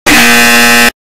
EXTREMELY LOUD INCORRECT BUZZER.m4a
[EXTREMELY LOUD INCORRECT BUZZER]